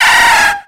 Cri de Phanpy dans Pokémon X et Y.